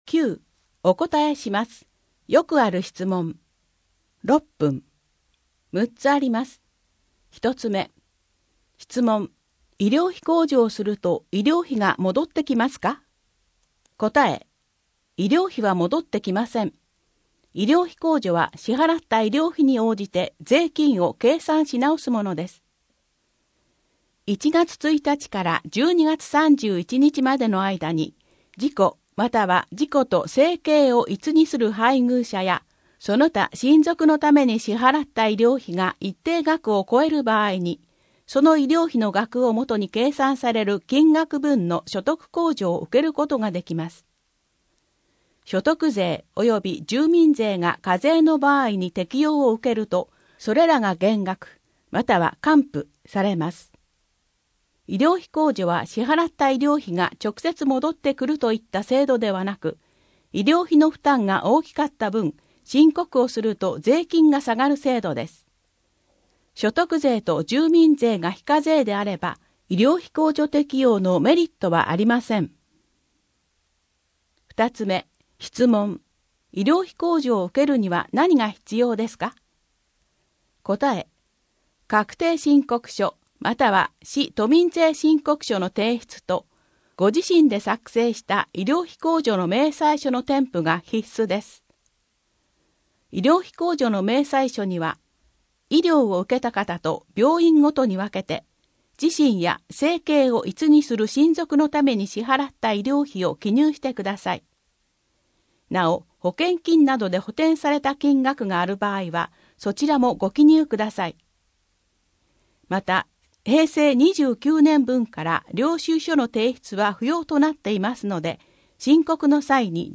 医療費控除について 2023年1月15日税の申告特集号2・3面PDF （PDF 4.9MB） 4面 よくある質問 東村山税務署からのお知らせ 2023年1月15日税の申告特集号4面PDF （PDF 458.4KB） 声の広報 声の広報は清瀬市公共刊行物音訳機関が制作しています。